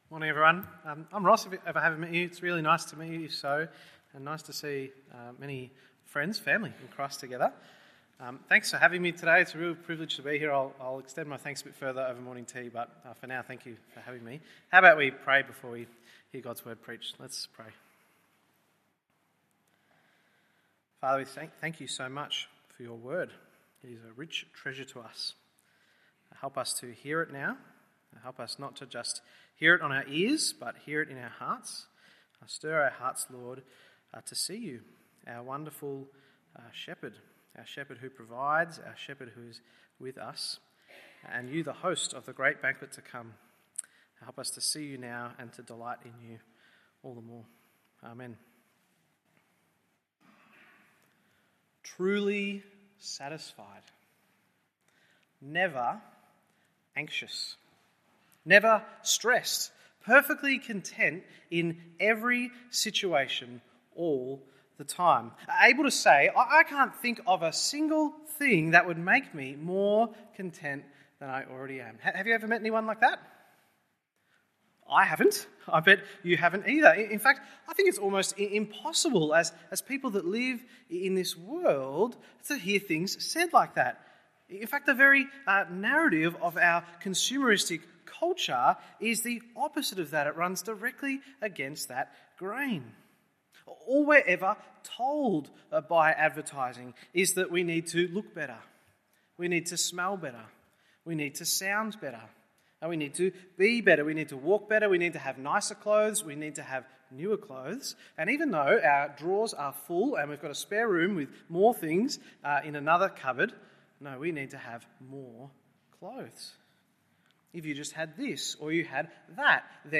MORNING SERVICE Psalm 23…